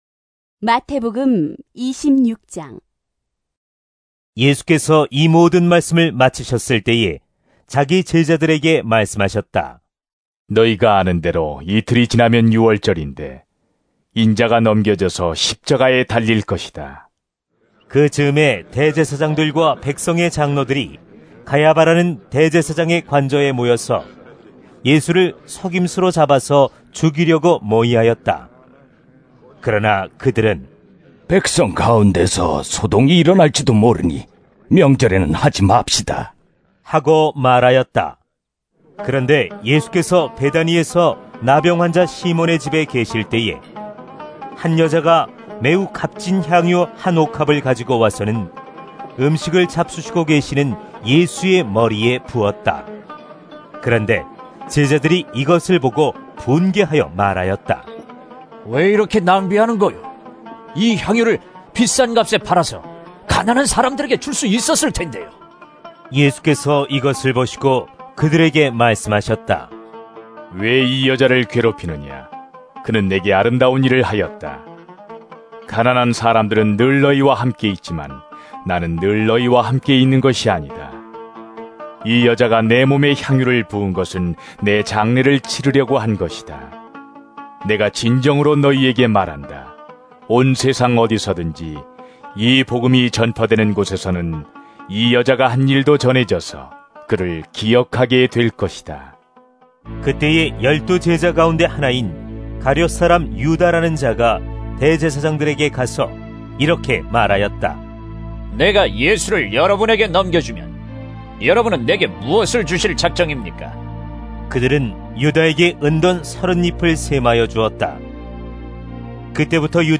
Audio Bible - Korean Audio Drama - New Testament (MP3) - Copy - DBS Digital Bibles, All Languages.